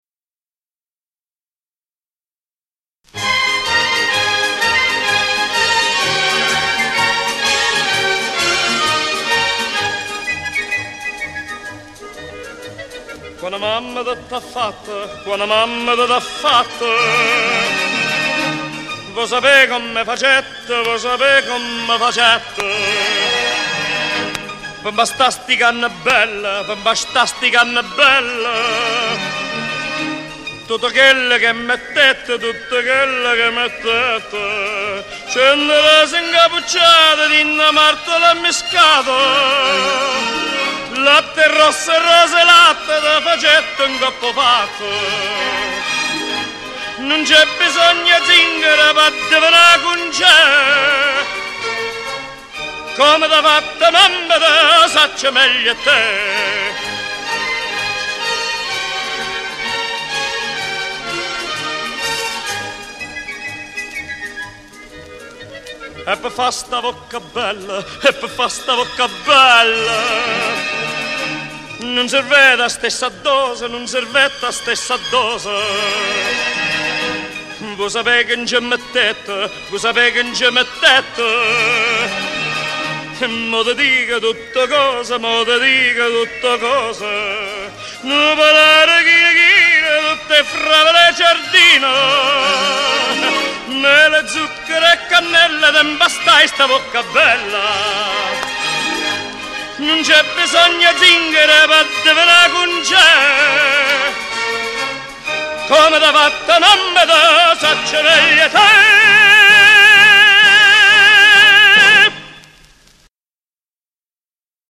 con accompagnamento orchestrale e coro